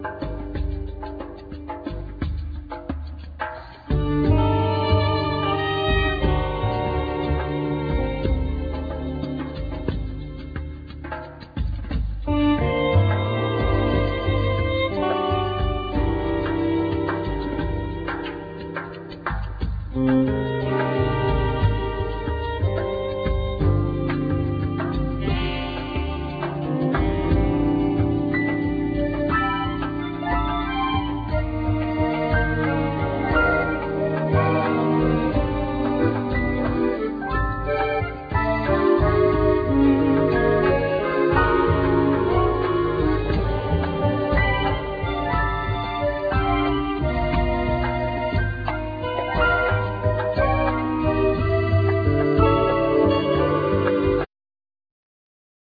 Guitar,Guitar Synth,Sample
Shakuhachi
Violin
Piano
Dumbek
Bass
Gaida,Kaval
Flute,Soprano+Alto+Tenor+Baritone Saxophone
Flugelhorn,Trumpet
Trombone
Tuba
Gakokoe(Bell)